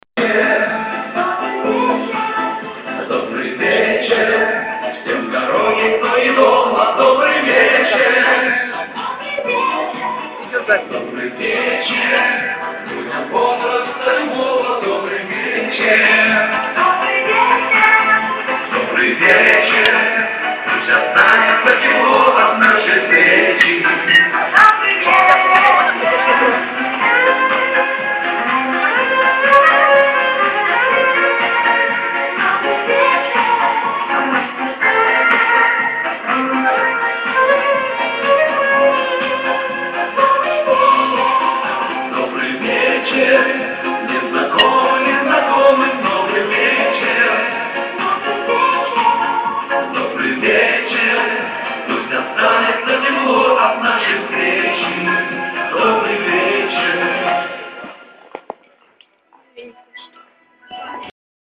Всем Привет! Друзья обращаюсь с просьбой В свое время я записал на мобильный телефон песню на вечере в ДК санатория Хорол г.Миргород Полтавской обл Исполнял ВИА этого же ДК Может у кого есть эта песня в в каком либо другом исполнении Или может ктото знает что нибудь об этой песне Поговорить с исполнителем этой песни мне тогда не удалось